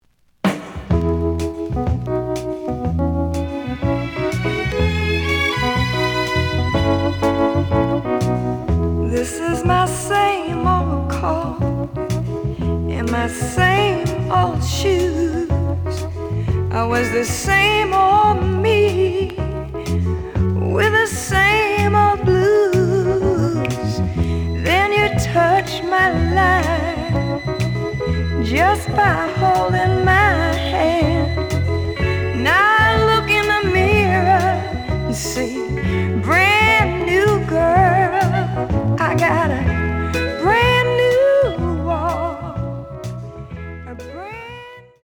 The audio sample is recorded from the actual item.
●Format: 7 inch
●Genre: Soul, 60's Soul